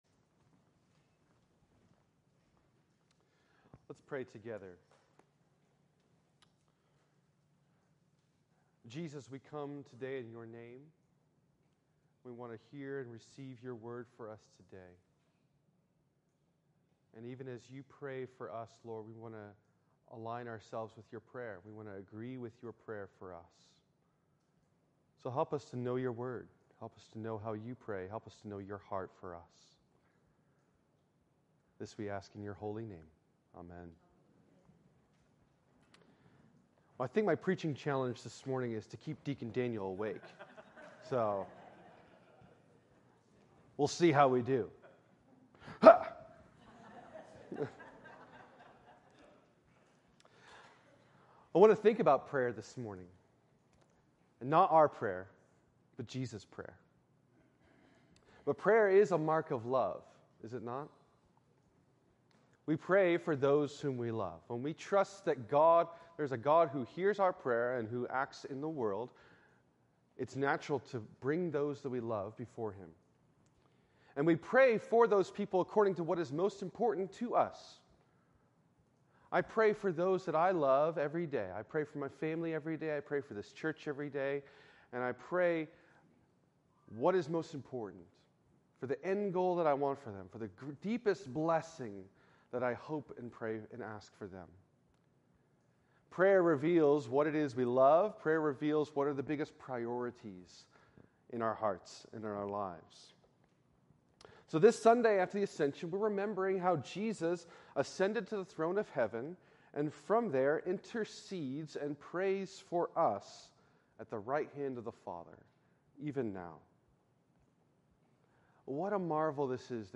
In this sermon on the Sunday after the Ascension